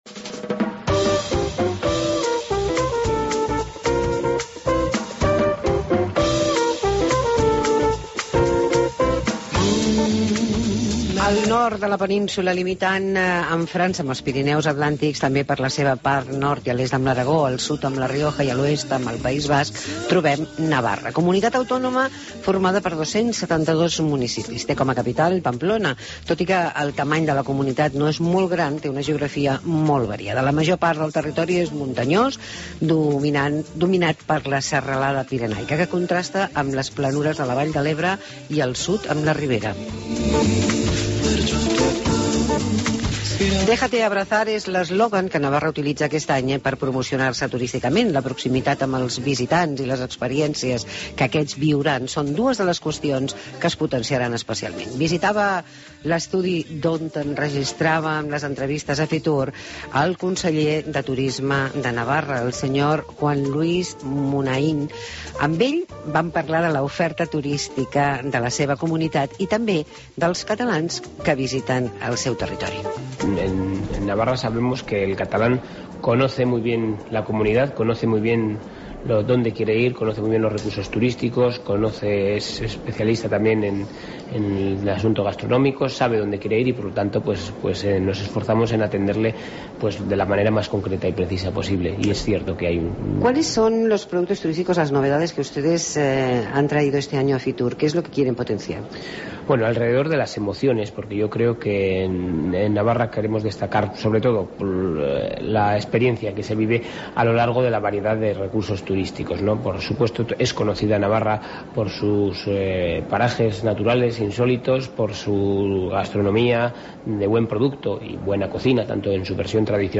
Entrevista con el concejero de turismo de Navarra, Juan Luis Sánchez de Muniaín